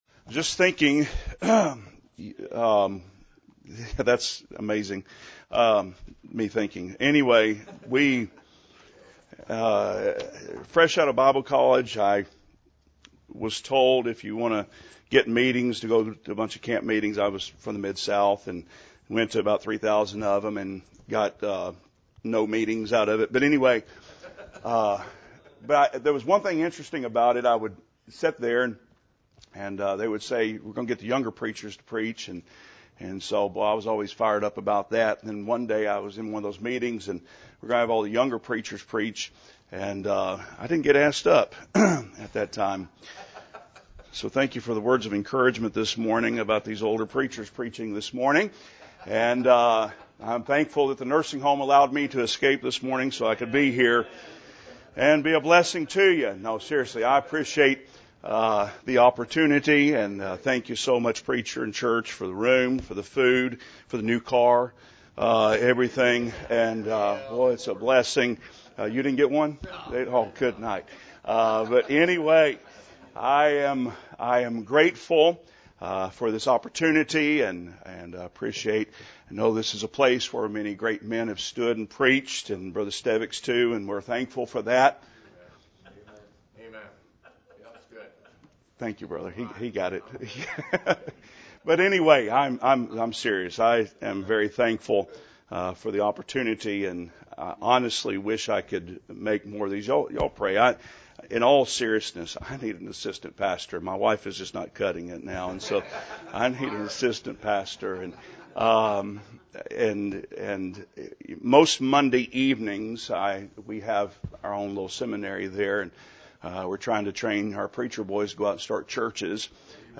during the Oklahoma Baptist Preacher’s Fellowship